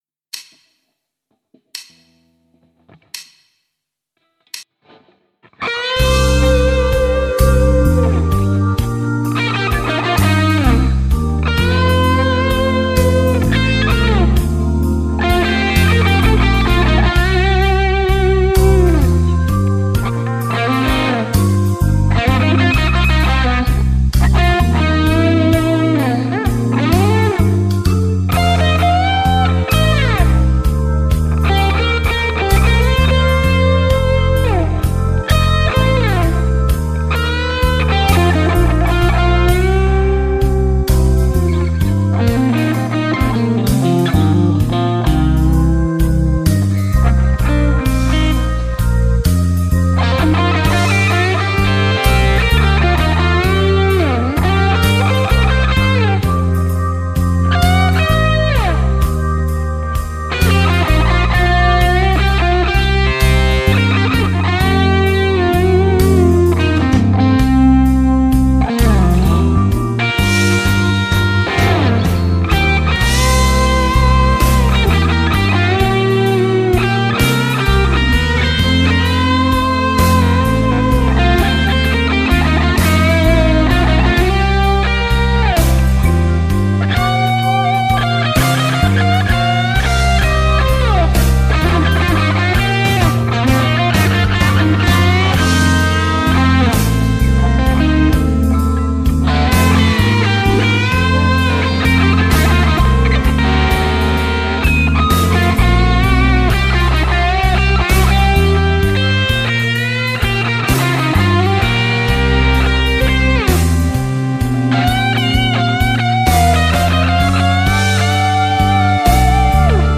- Soita annetun taustan päälle solistinen osuus valitsemallasi instrumentilla
kuulauden aalloilla surffaillaan... 1 p
Jäntevää intensiteettiä.